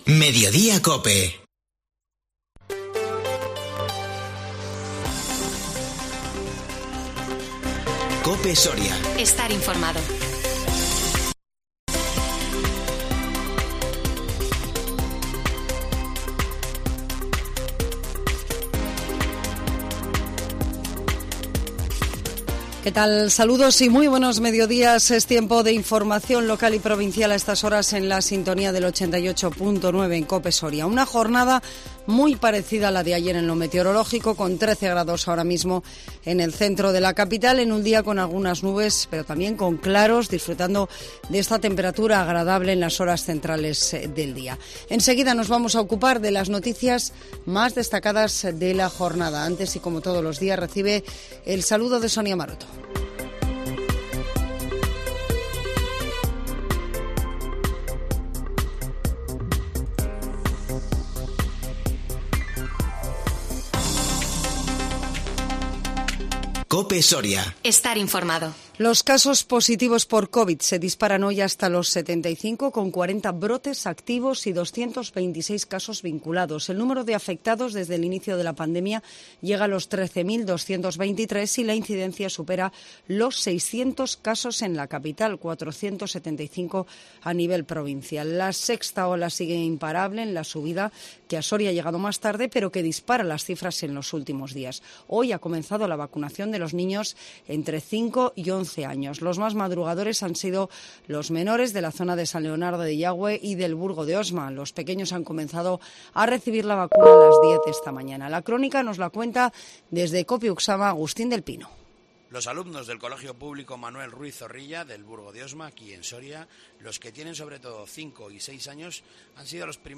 INFORMATIVO MEDIODÍA 15 DICIEMBRE 2021